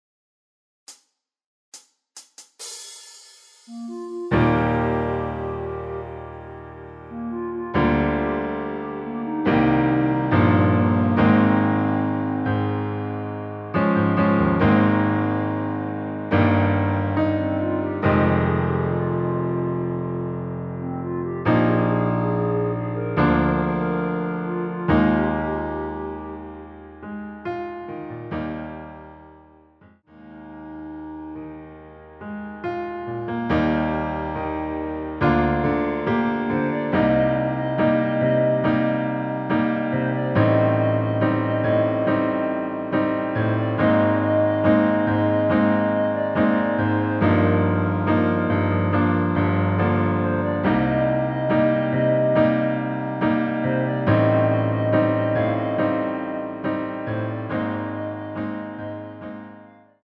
반주가 피아노 하나만으로 제작 되었습니다.(미리듣기 확인)
전주없이 노래가시작되는곡이라 카운트 만들어 놓았습니다.
Eb
앞부분30초, 뒷부분30초씩 편집해서 올려 드리고 있습니다.
중간에 음이 끈어지고 다시 나오는 이유는